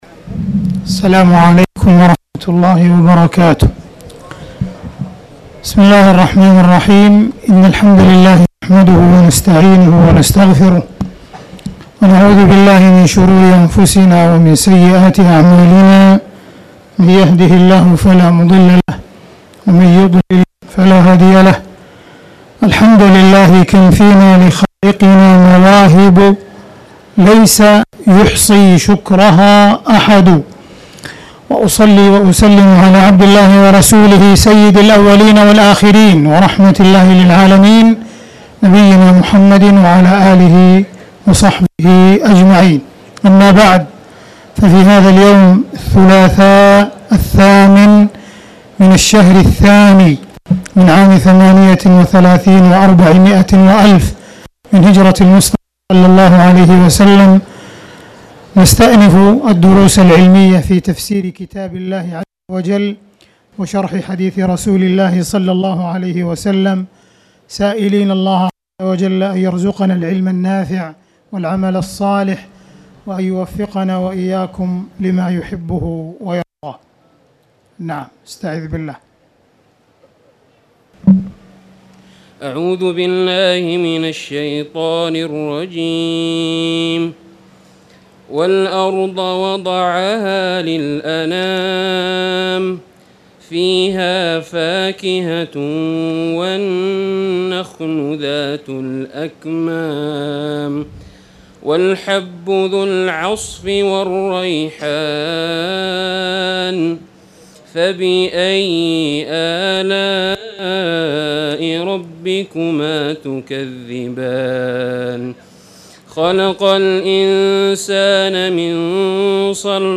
تاريخ النشر ٨ صفر ١٤٣٨ هـ المكان: المسجد الحرام الشيخ: معالي الشيخ أ.د. عبدالرحمن بن عبدالعزيز السديس معالي الشيخ أ.د. عبدالرحمن بن عبدالعزيز السديس سورة الرحمن